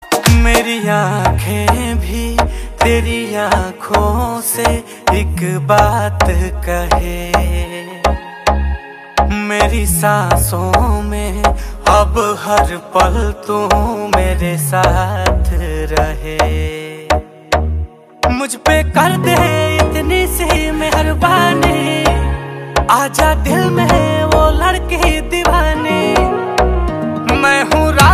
Hindi song